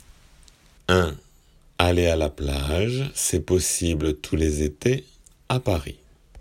仏検受験用　聞き取り正誤問題－音声